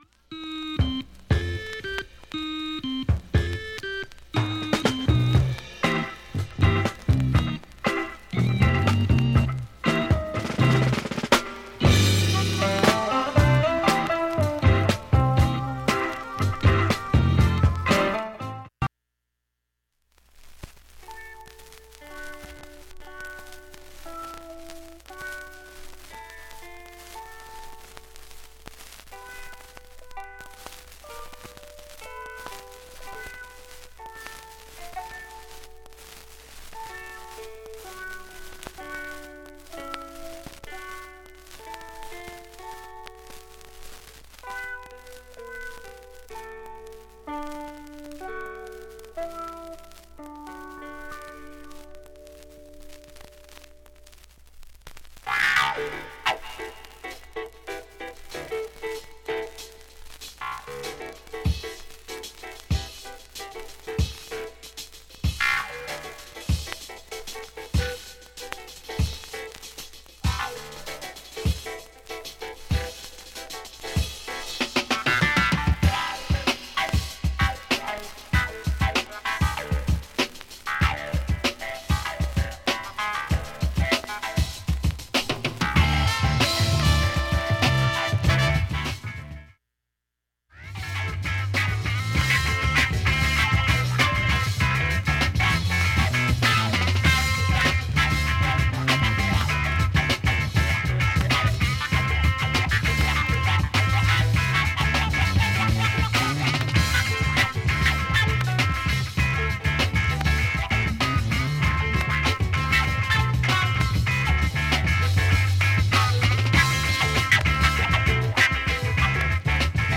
曲間などもチリもほぼなく
普通に聴けます音質良好全曲試聴済み。
５回までのかすかなプツが１箇所
３回までのかすかなプツが４箇所
単発のかすかなプツが４箇所